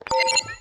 UIBeep_Notification.wav